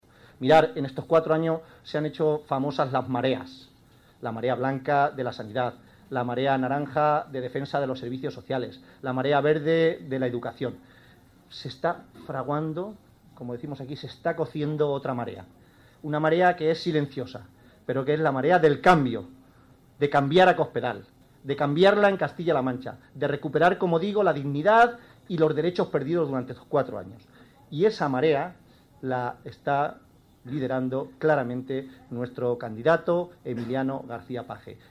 Audio Page en La Solana 4